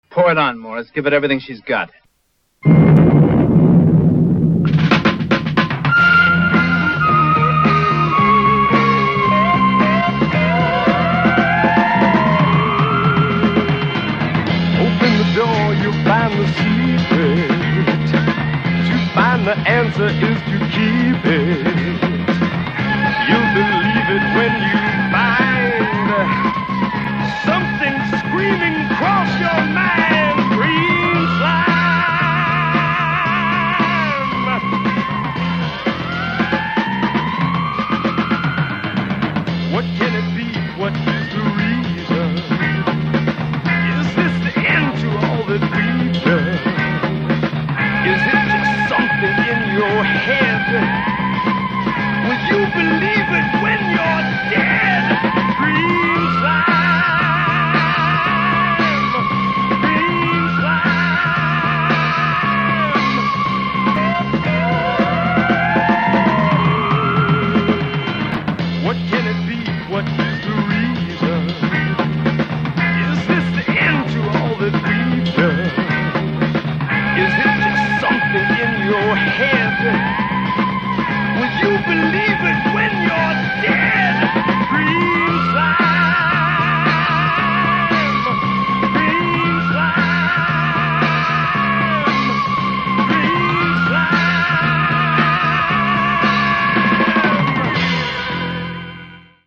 slimy soundtrack